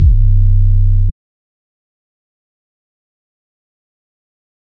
808 (Skyfall).wav